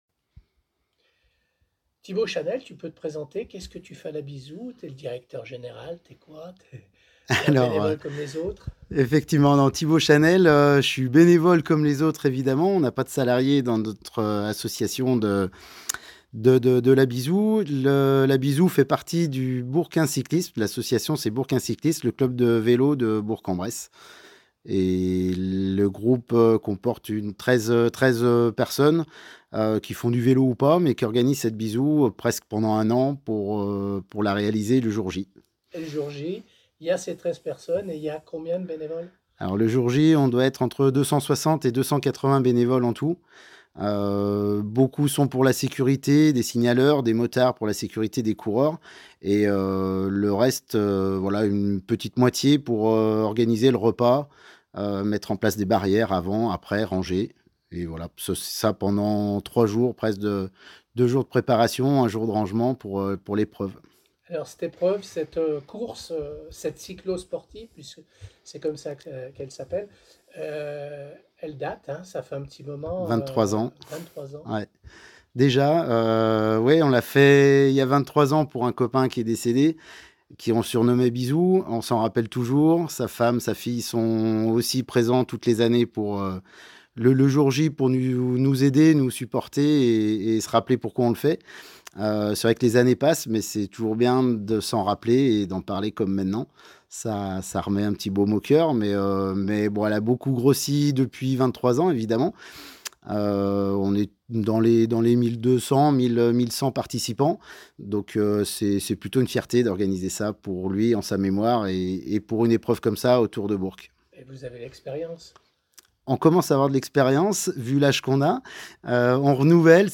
Interview Radio Scoop